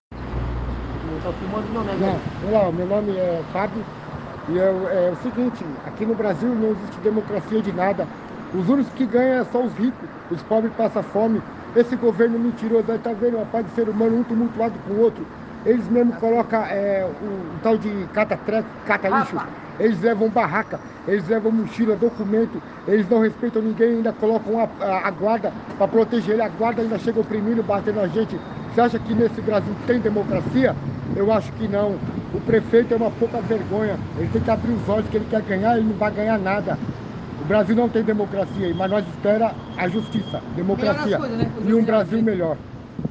E falamos com um morador em situação de rua se ele acredita que existe democracia no Brasil.
orador-em-situacao-de-rua-ao-lado-da-Educafro.mp3